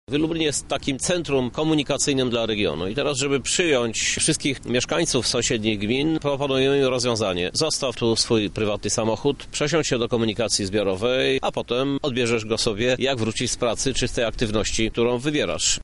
Jest to odpowiedź na zgłaszane potrzeby mieszkańców – mówi Prezydent Miasta, Krzysztof Żuk: